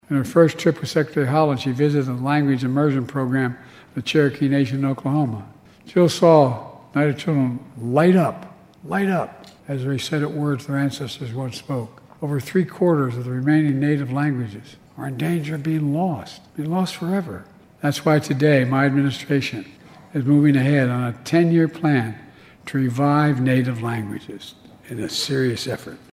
During his remarks to tribal leaders, President Joe Biden told about an impactful visit by First Lady Jill Biden in 2021 to the Cherokee Nation Immersion School in Park Hill and how it led h
President Biden on Cherokee Immersion School 12-09.mp3